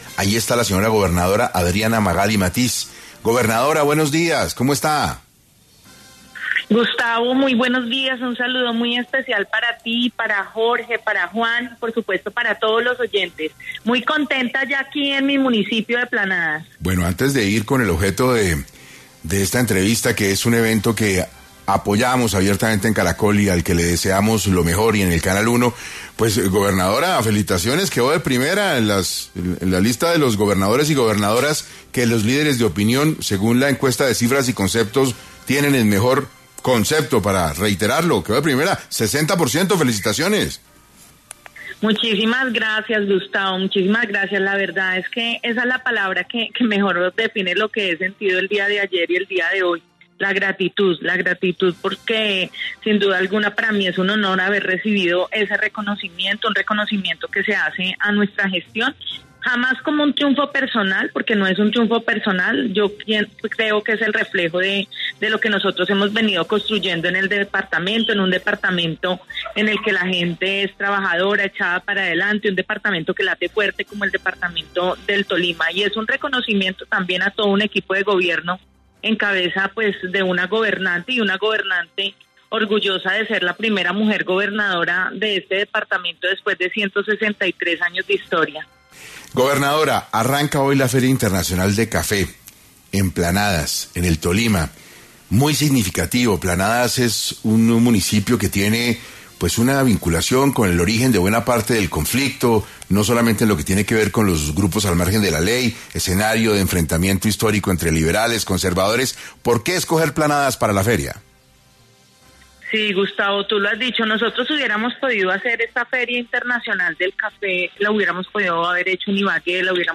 La gobernadora del Tolima, Adriana Magali Matiz, estuvo en 6AM para ampliar la información sobre la Feria Internacional del Café en Planadas.
Adriana Magali Matiz, gobernadora del Tolima, pasó por los micrófonos de 6AM para profundizar los temas más relevantes alrededor de la Feria Internacional del Café en Planadas.